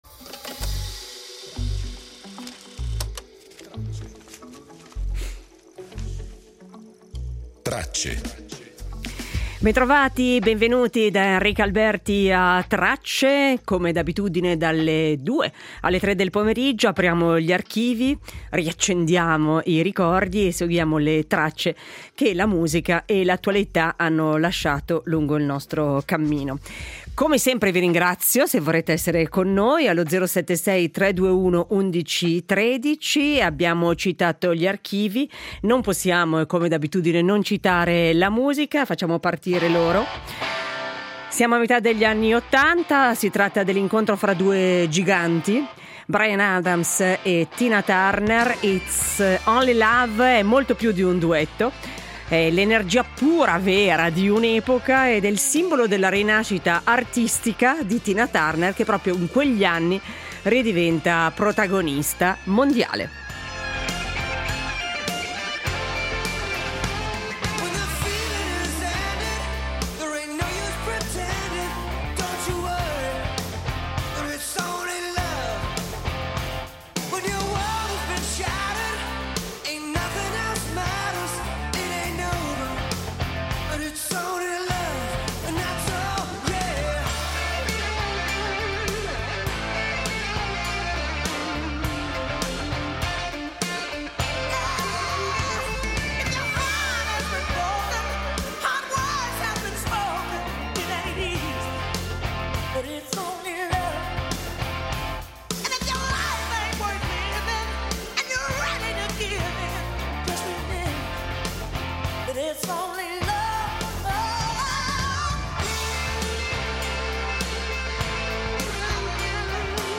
Riascoltiamo un altro nato del 23 marzo, un giovane Ugo Tognazzi intercettato dai nostri microfoni nel 1956. Nel giorno in cui è mancata all’età di 79 anni nel 2011 rievochiamo l’ultima diva dell’età dell’oro di Hollywood, l’attrice dagli occhi viola Elizabeth Taylor.